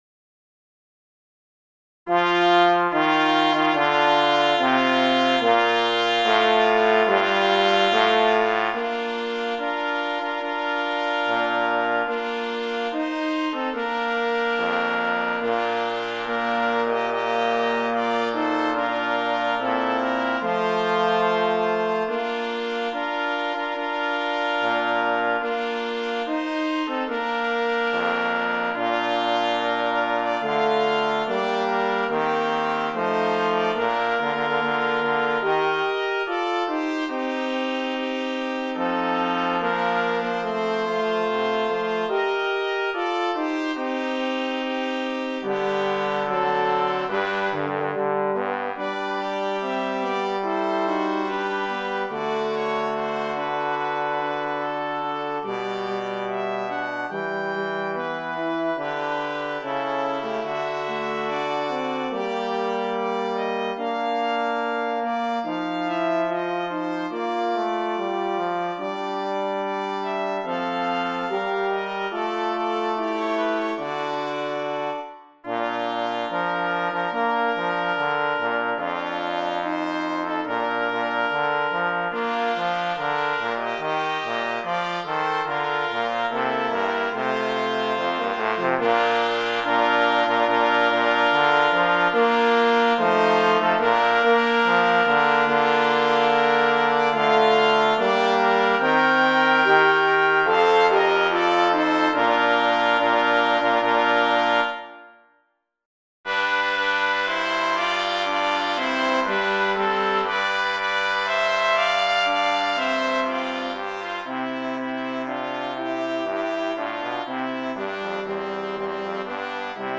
Voicing: Brass Quartet